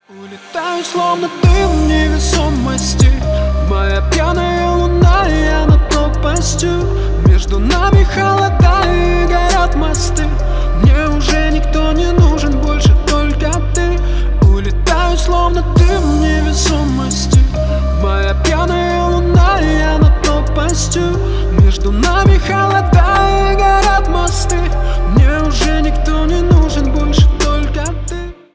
грустные , лирика
поп